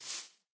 grass4.ogg